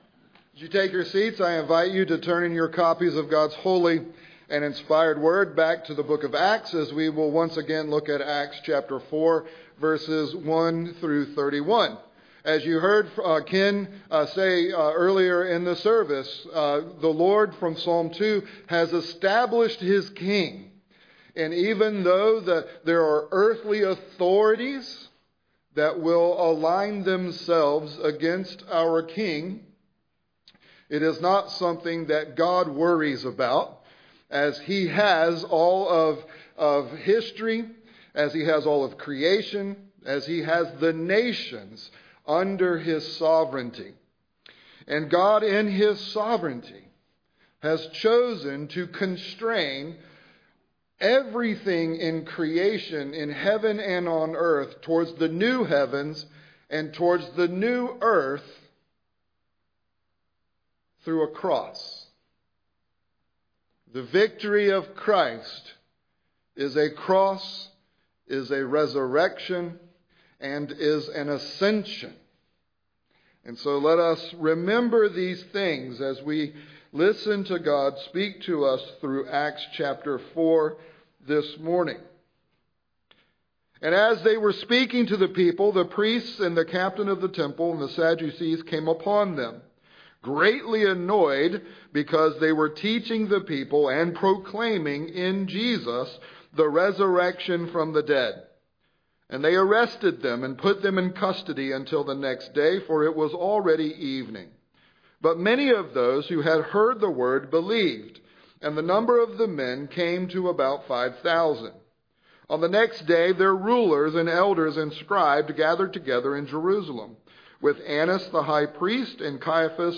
Spirit-Filled, Cruciform Boldness II ~ Sermons Podcast